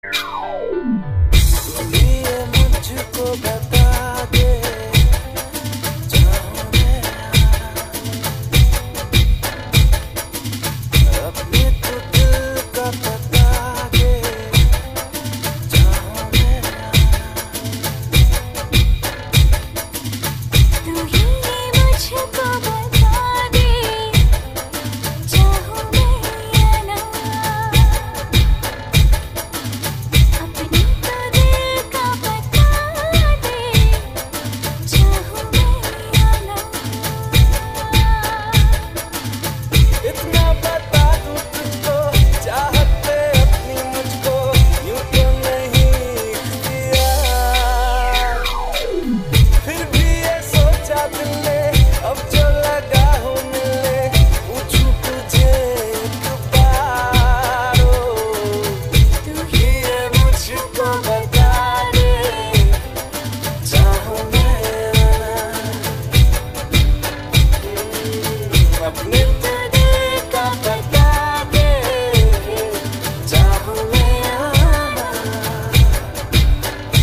File Type : Navratri dandiya ringtones